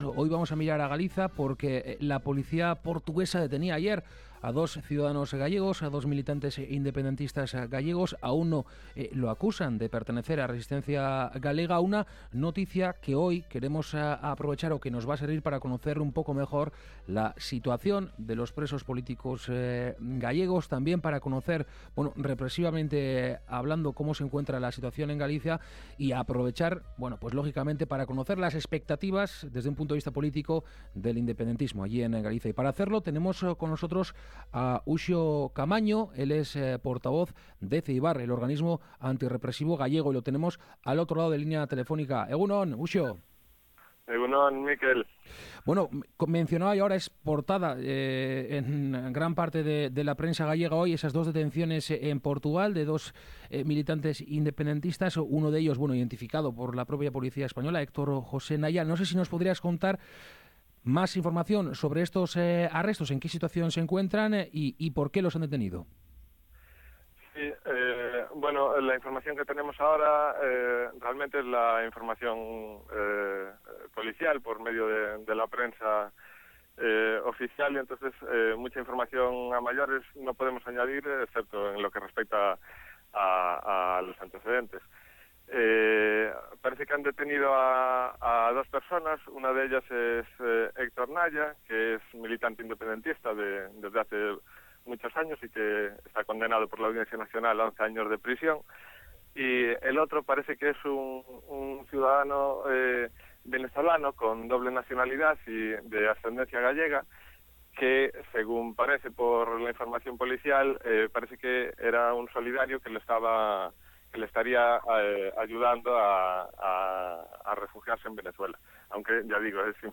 entrevistar